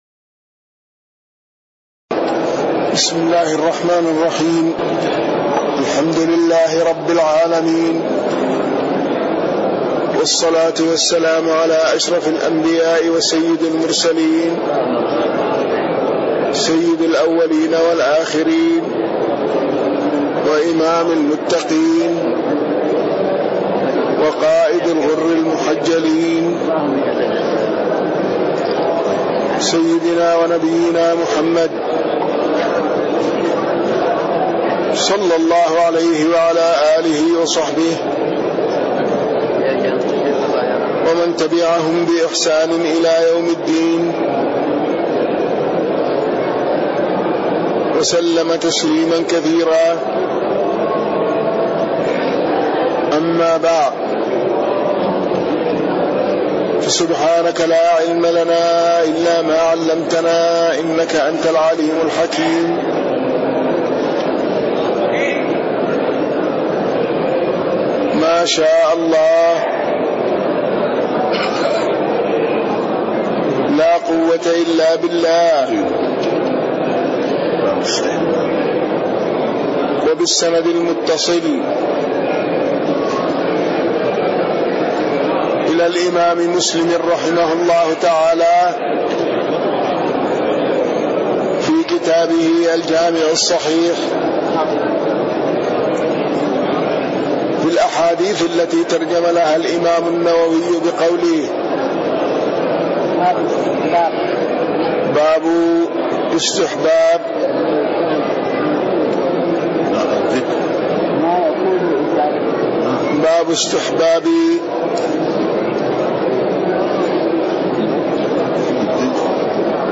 تاريخ النشر ١٦ ربيع الثاني ١٤٣٤ هـ المكان: المسجد النبوي الشيخ